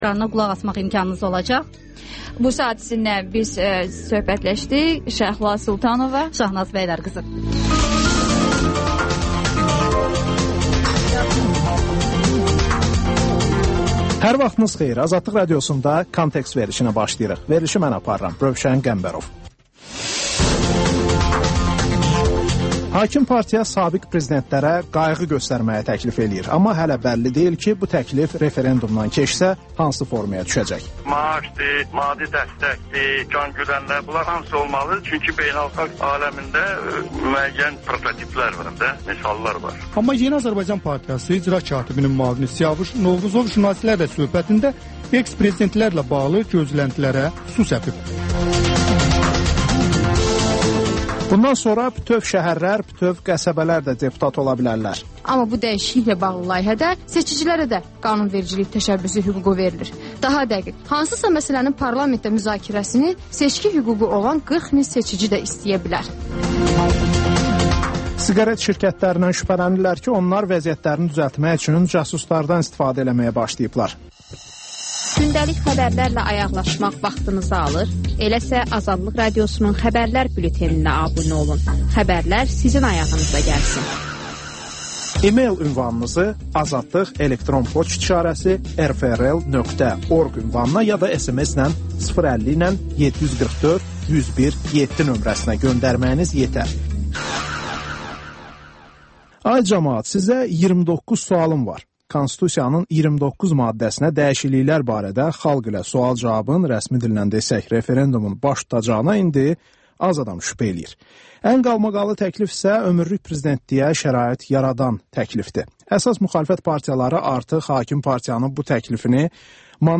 Ölkənin tanınmış simalarıyla söhbət (Təkrar)